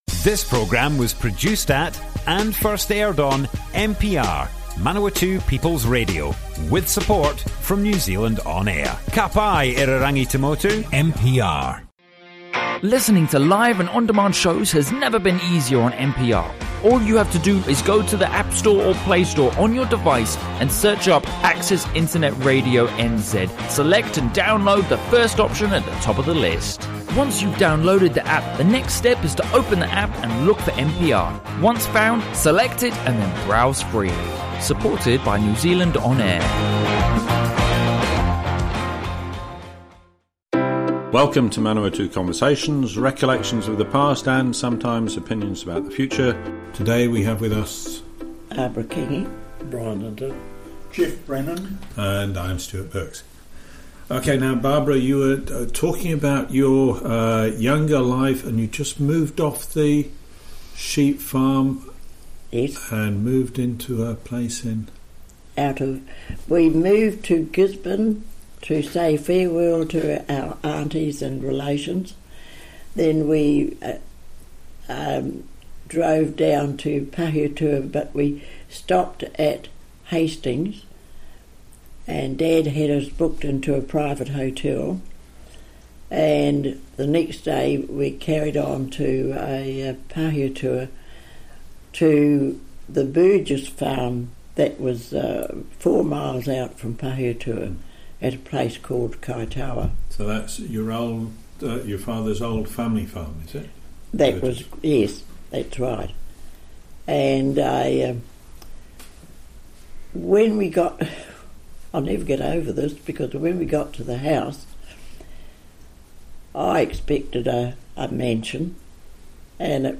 Manawatu Conversations More Info → Description Broadcast on Manawatu People's Radio, 24th December 2019.
oral history